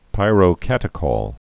(pīrō-kătĭ-kôl, -kŏl, -kōl)